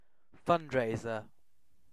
Uttal
Alternativa stavningar fund-raiser Uttal UK Ordet hittades på dessa språk: engelska Ingen översättning hittades i den valda målspråket.